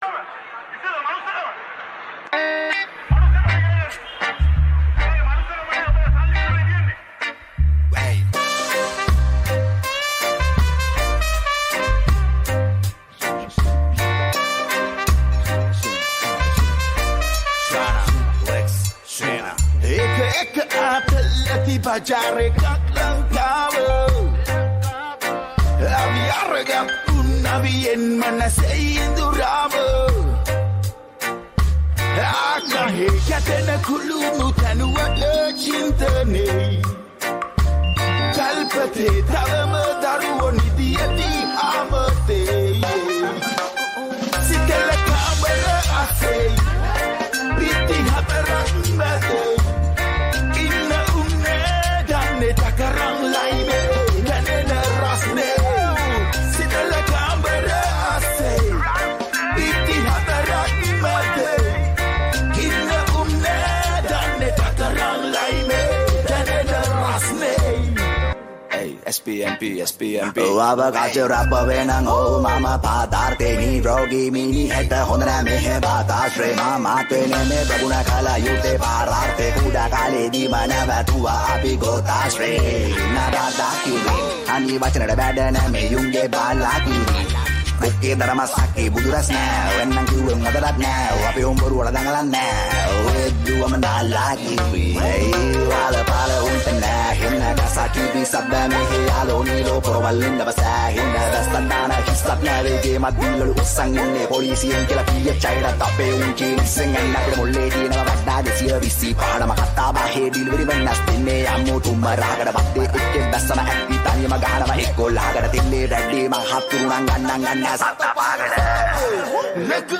Reggae & Hip Hop Mixed Creation